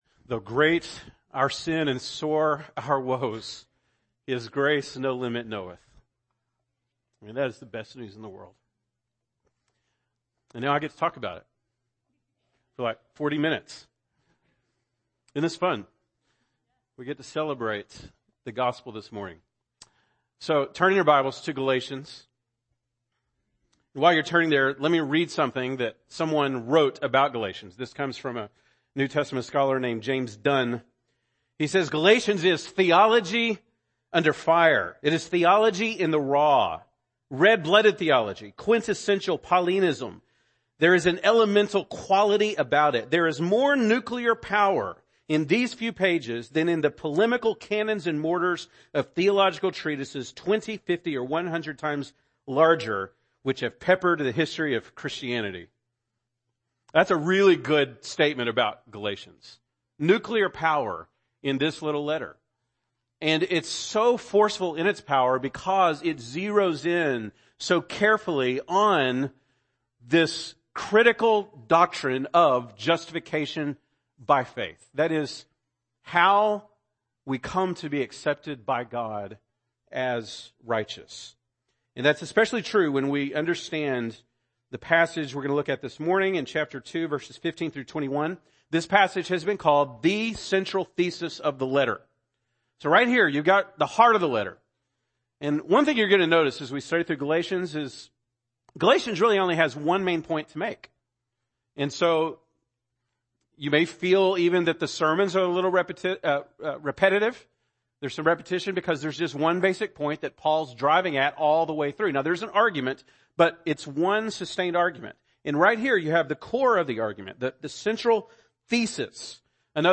September 17, 2017 (Sunday Morning)